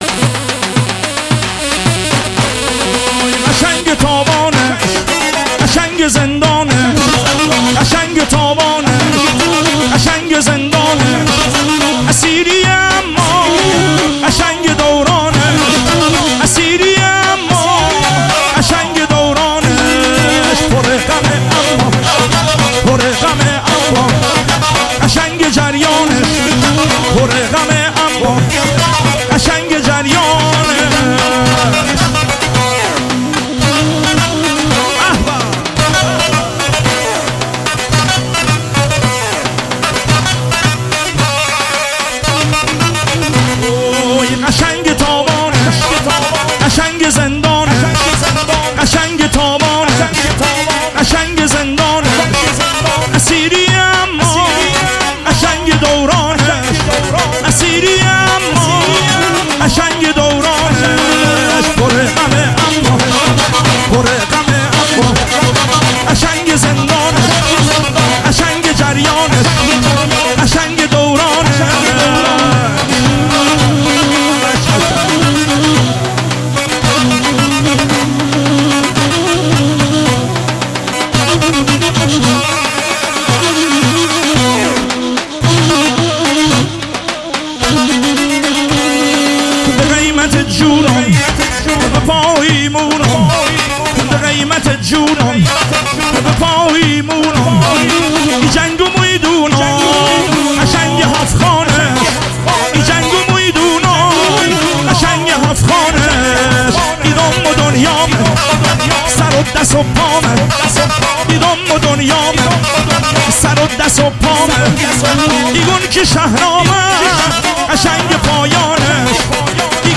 محلی لری عروسی